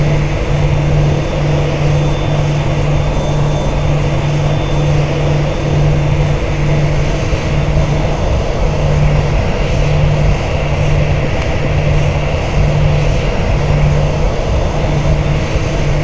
x320_idle_rear.wav